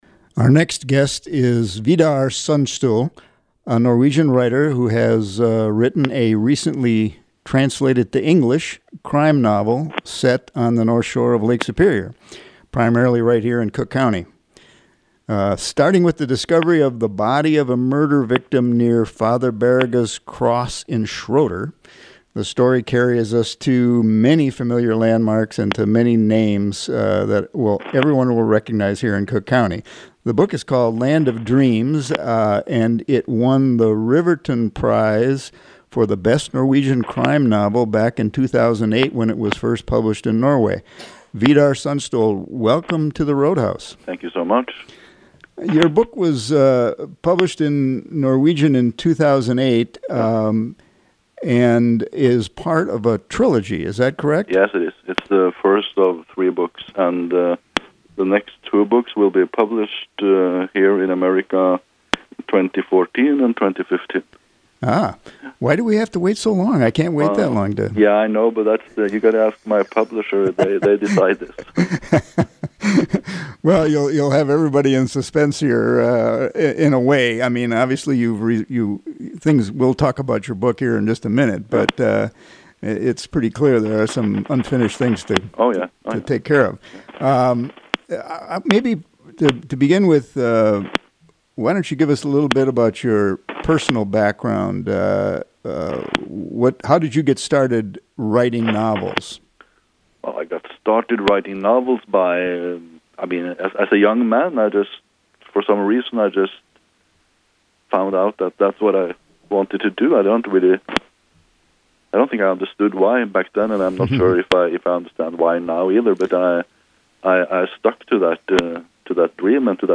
Norwegian author